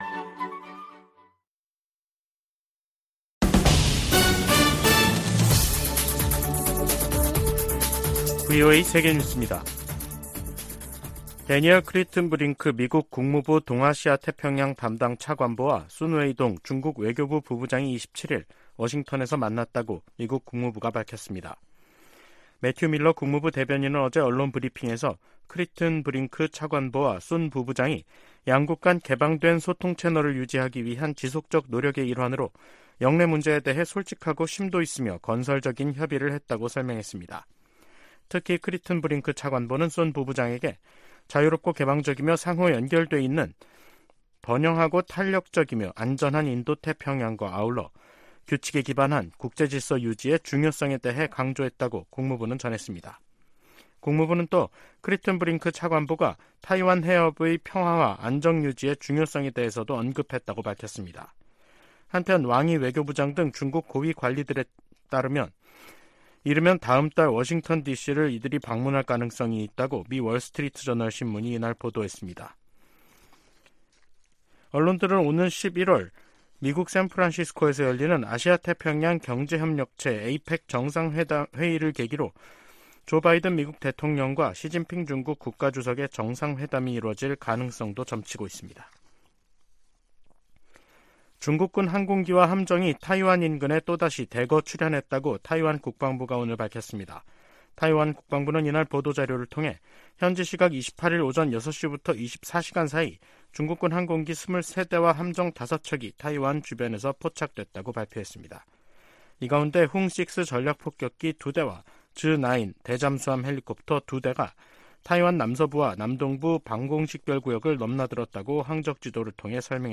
VOA 한국어 간판 뉴스 프로그램 '뉴스 투데이', 2023년 9월 29일 2부 방송입니다. 미 국무부는 북한의 핵무력 정책 헌법화를 비판하고, 평화의 실행 가능 경로는 외교뿐이라고 지적했습니다. 미 국방부는 북한이 전쟁의 어떤 단계에서도 핵무기를 사용할 수 있으며, 수천 톤에 달하는 화학 물질도 보유하고 있다고 밝혔습니다.